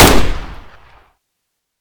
ak105_shoot.ogg